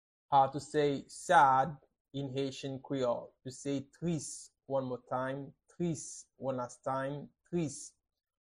How to say "Sad" in Haitian Creole - "Tris" pronunciation by a private Haitian Creole teacher
“Tris” Pronunciation in Haitian Creole by a native Haitian can be heard in the audio here or in the video below: